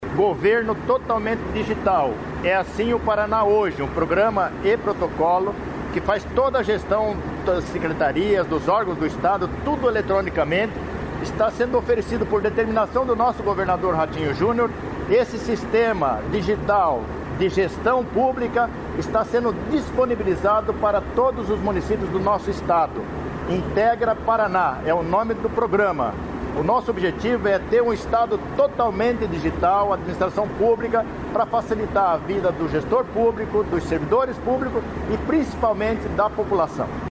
Sonora do secretário de Estado da Administração e da Previdência, Luizão Goulart, sobre o programa Integra Paraná